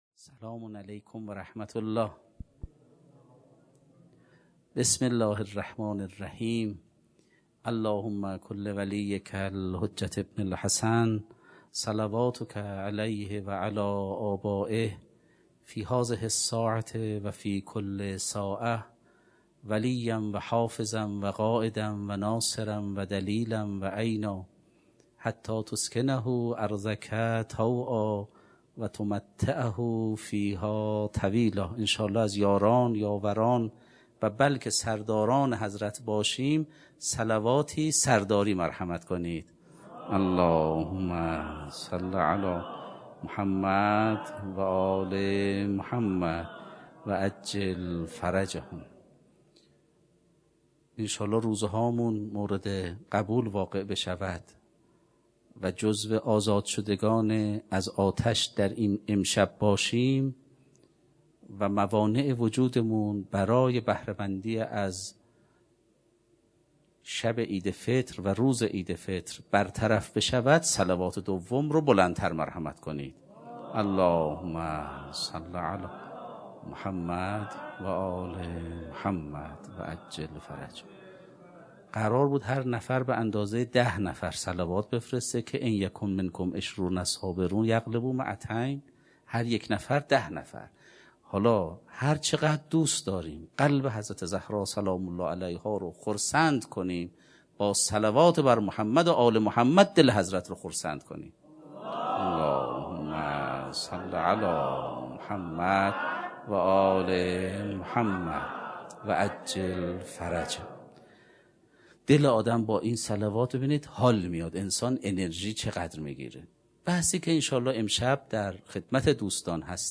سخنرانی: تواصی